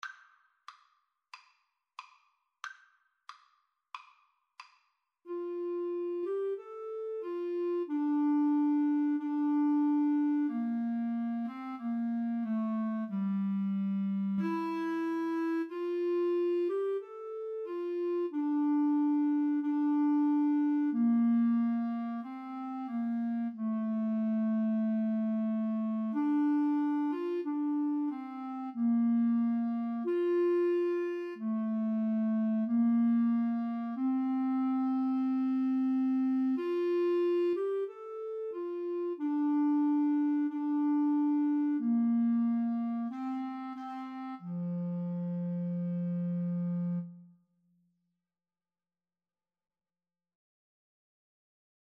Clarinet-Violin Duet version
Andante = c. 92